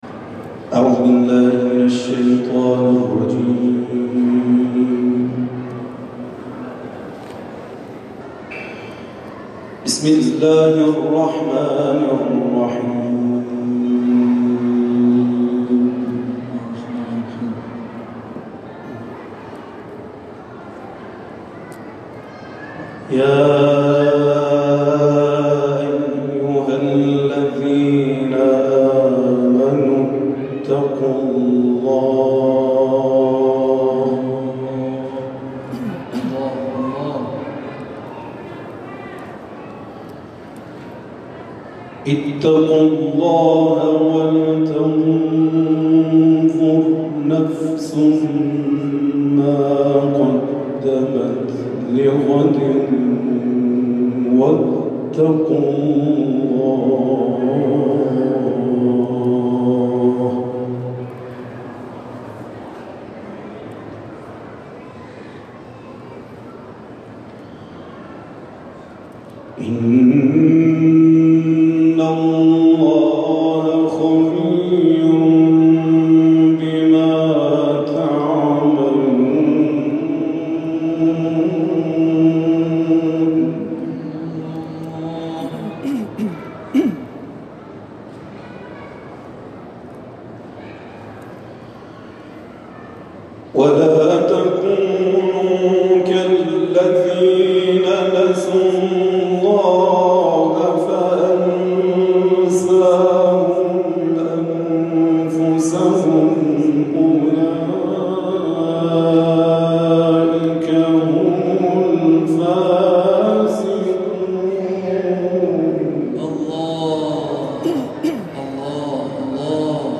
گروه شبکه‌های اجتماعی ــ قاری بین‌المللی کشور، آیات سوره‌های مبارکه حشر و اعلی را در محفل کرسی تلاوت عبدالعظیم(ع) تلاوت کرده است.